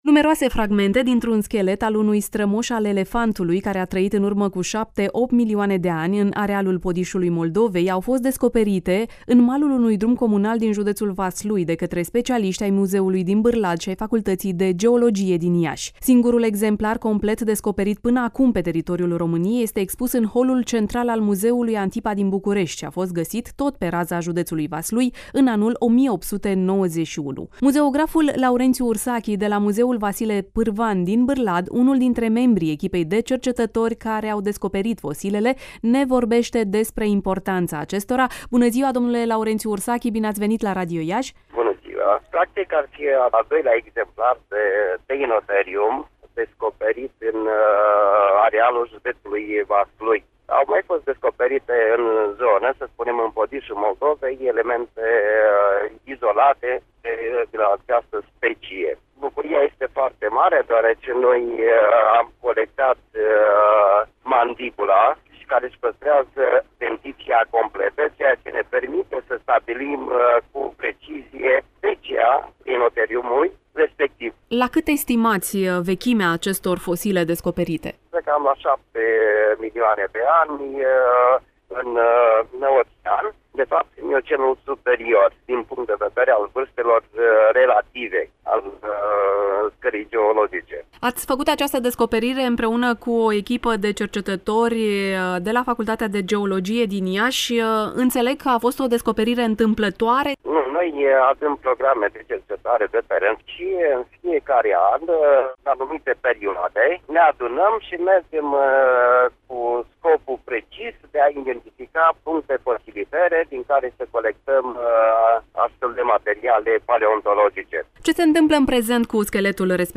(AUDIO/INTERVIU) Fragmente dintr-un schelet al unui strămoș al elefantului care a trăit în urmă cu 7- 8 milioane de ani, descoperite într-o comună din Vaslui